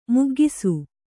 ♪ muggisu